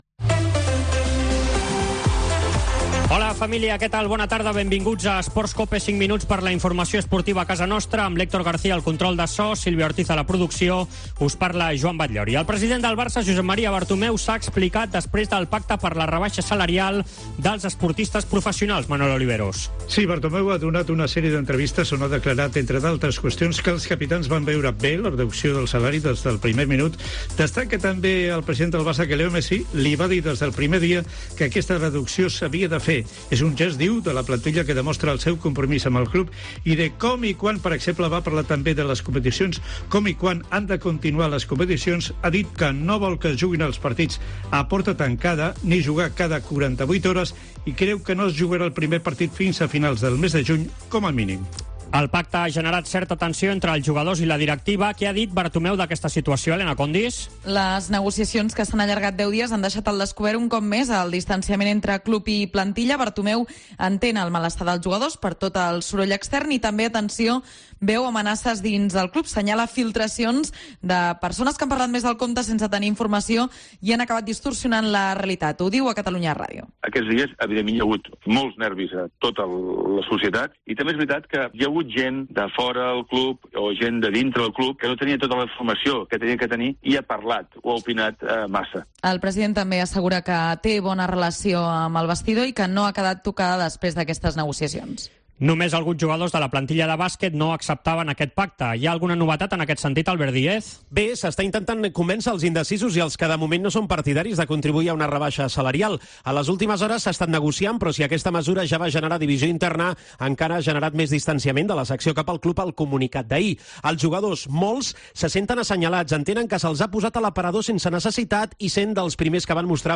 tot l'equip treballant des de casa.